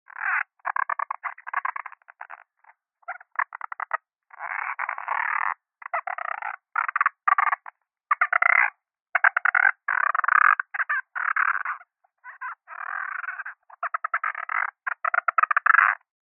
Звуки чужого
Звук, в котором пришелец пытается произнести слова